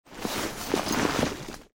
Звуки портфеля
Звук доставания вещей из сумки